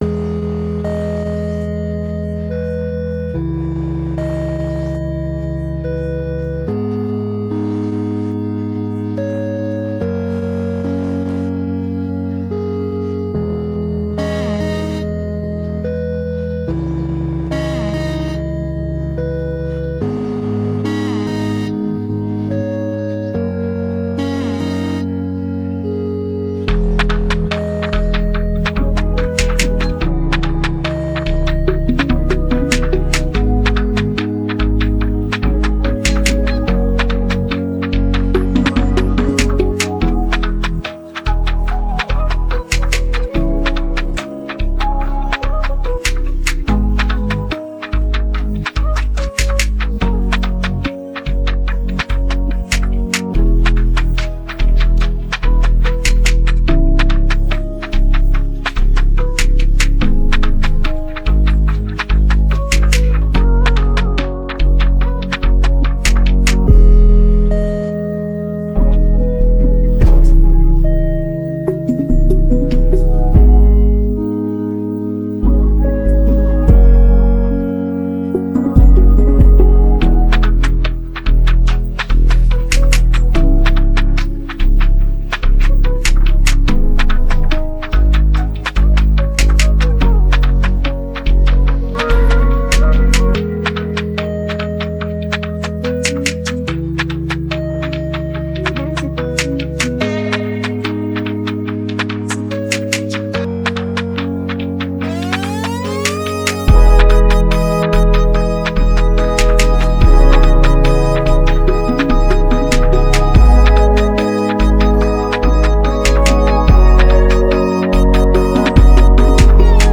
Afro trapPop